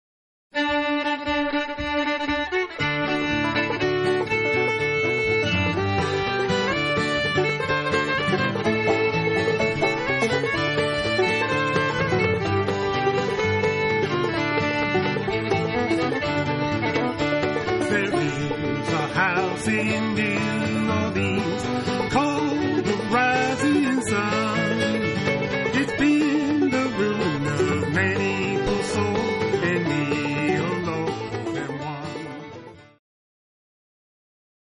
blues classic